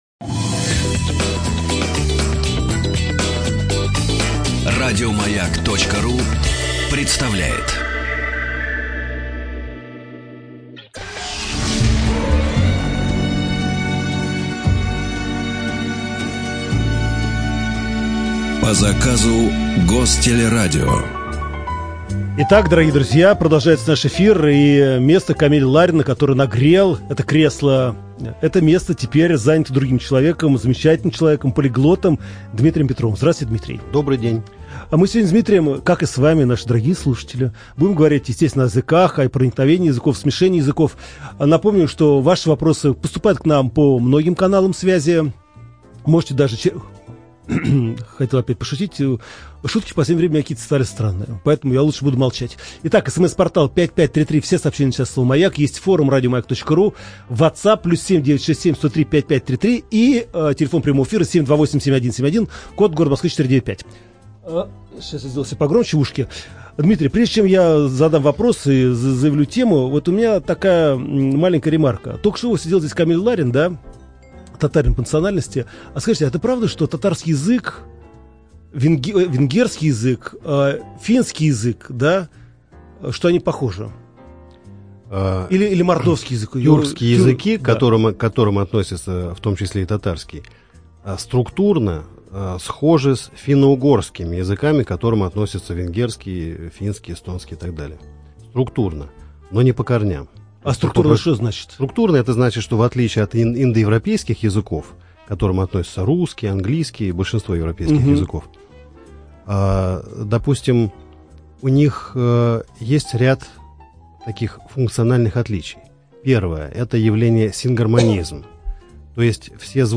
ЧитаетАвтор
ЖанрНаука и образование, Радиопрограммы